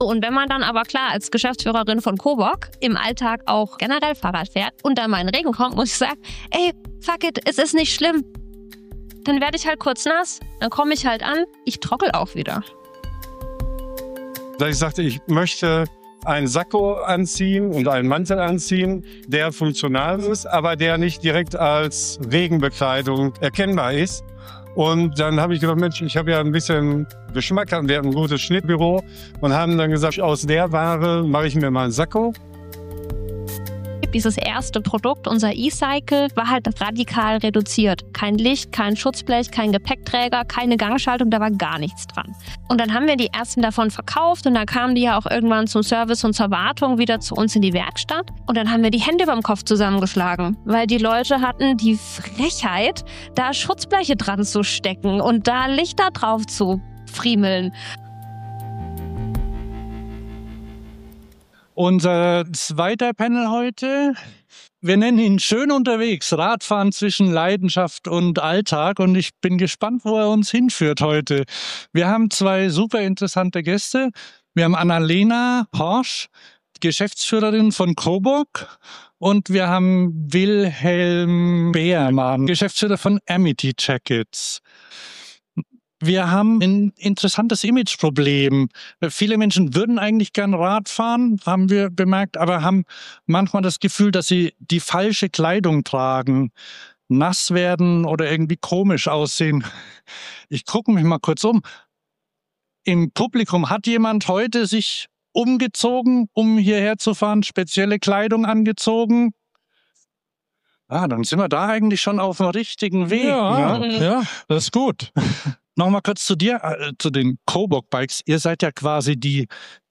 Cyclingworld 2026 live – Schön unterwegs, Radfahren zwischen Leidenschaft und Alltag – 2020 ~ Fahrradio Podcast